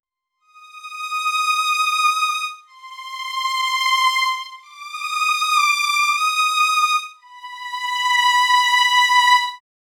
Crescendo Magical Strings
crescendo-magical-strings-mzhfgugr.wav